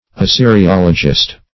Search Result for " assyriologist" : The Collaborative International Dictionary of English v.0.48: Assyriologist \As*syr`i*ol"o*gist\, n. One versed in Assyriology; a student of Assyrian arch[ae]ology.